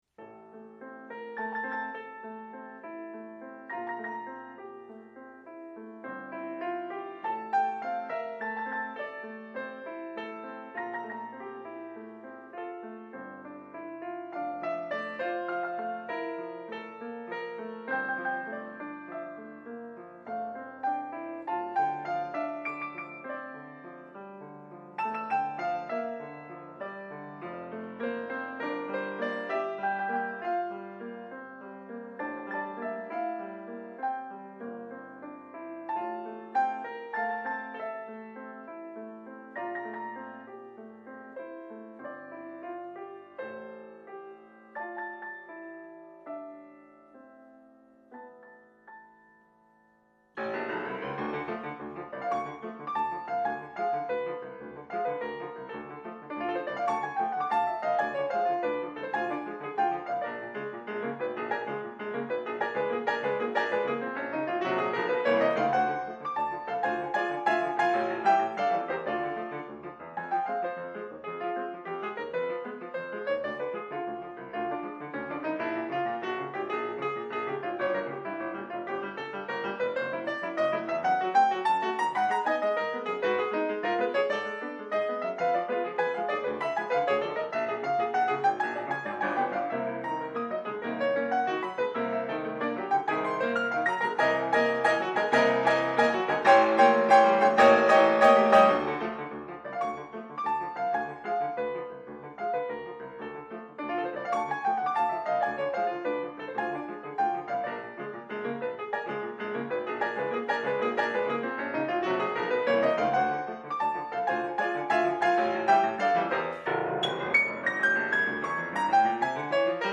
für Klavier zu 4 Händen